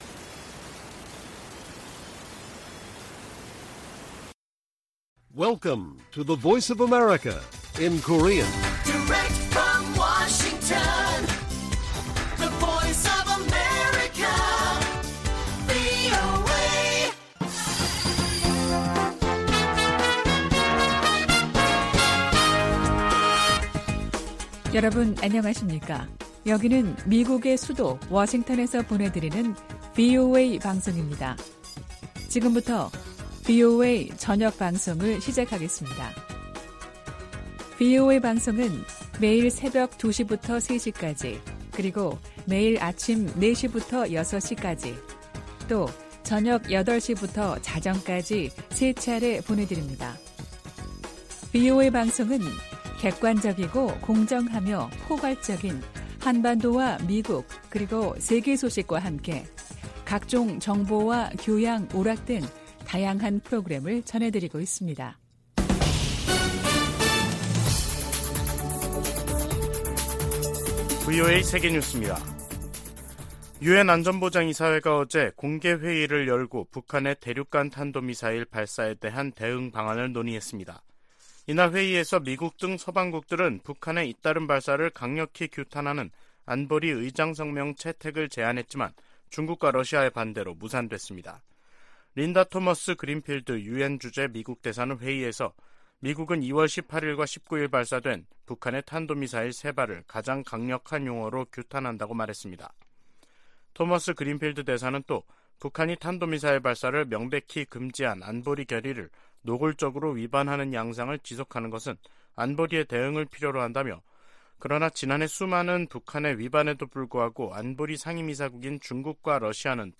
VOA 한국어 간판 뉴스 프로그램 '뉴스 투데이', 2023년 2월 21일 1부 방송입니다. 유엔 안전보장이사회가 북한의 대륙간탄도미사일(ICBM) 발사에 대응한 공개회의를 개최한 가운데 미국은 의장성명을 다시 추진하겠다고 밝혔습니다.